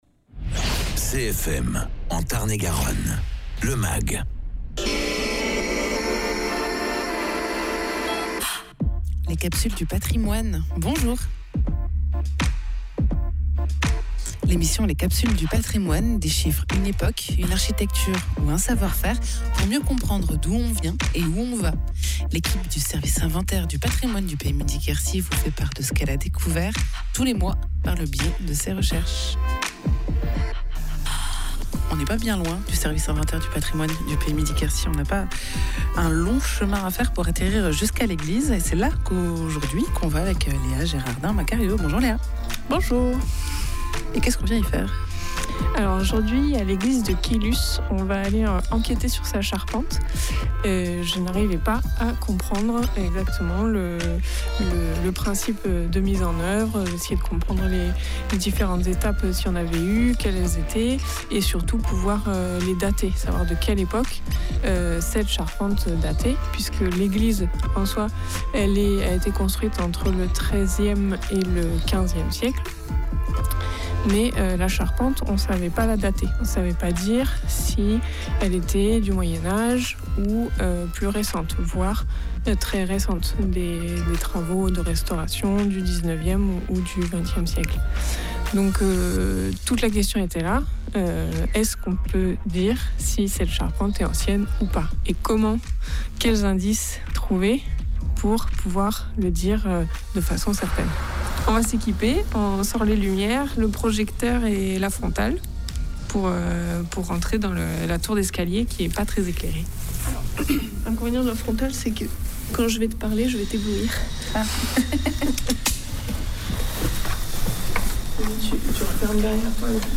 Reportage dans la charpente de l’église de Caylus
Interviews